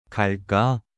• ㄱ (g/k): 갈까 (Gal-kka) (Vamos?)